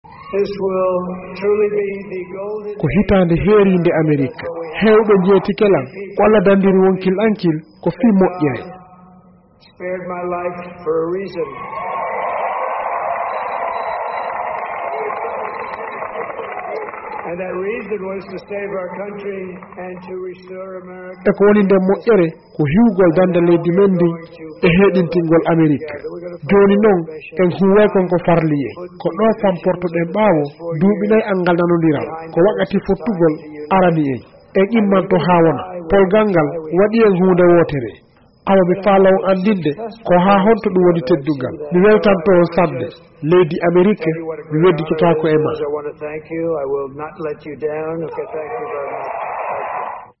Donald Trump ƴetti konngol kisan caggal mediyaajiɗin fellintinnde kawu makko e ɗii suɓngooji hooreyaaku talaata 5, 11ɓuru. Ameriknaaɓe ɓen suɓikemo junngo dow yeeso Jukko lamɗo Kamala Harris.